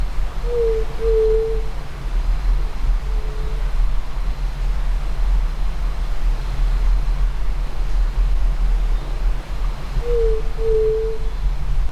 White-tipped Dove (Leptotila verreauxi)
Life Stage: Adult
Location or protected area: Salvador Mazza- La Toma
Condition: Wild
Certainty: Observed, Recorded vocal